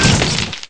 crashwd.wav